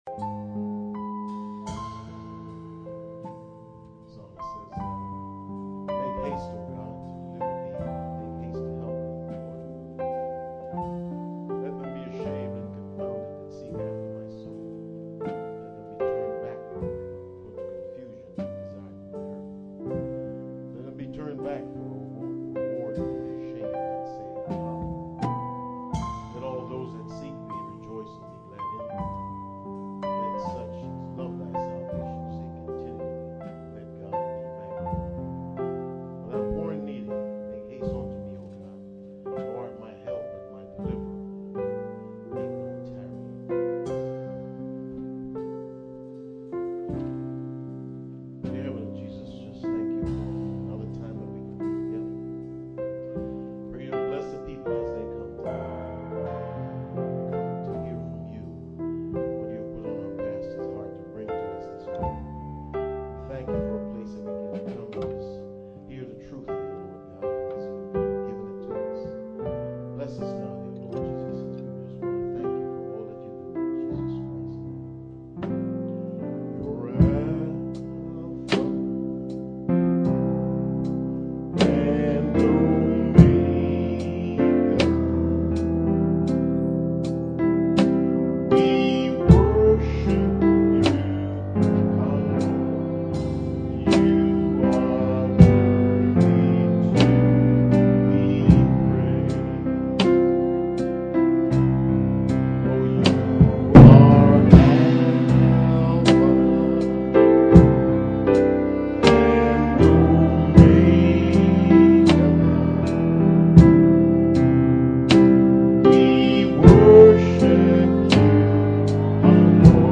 2 Timothy 3:16-17 Service Type: Sunday Morning %todo_render% « Insufficient Funds Study on I Samuel 22